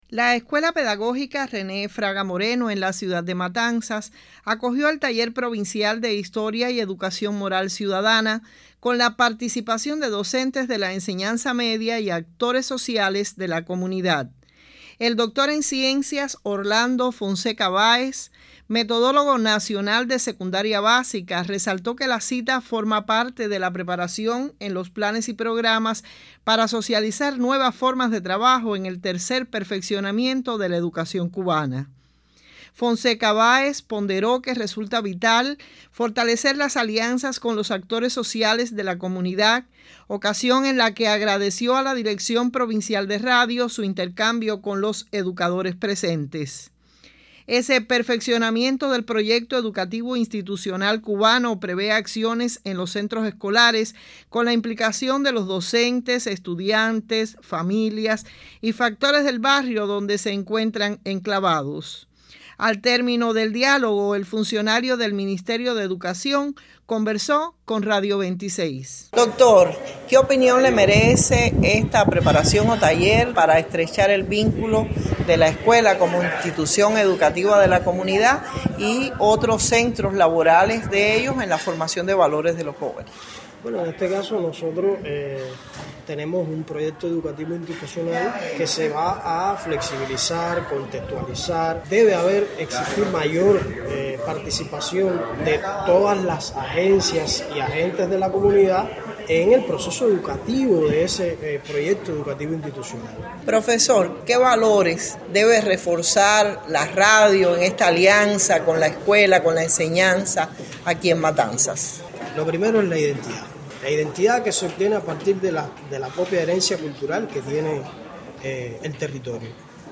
Al término del diálogo el funcionario del Ministerio de Educación conversó con Radio 26: